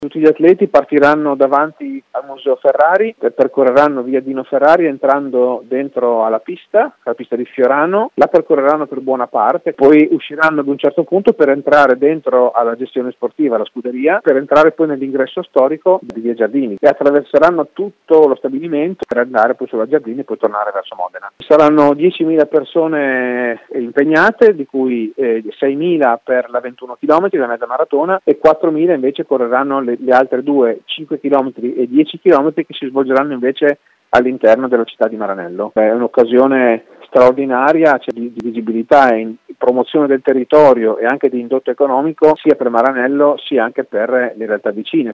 Il sindaco di Maranello Luigi Zironi: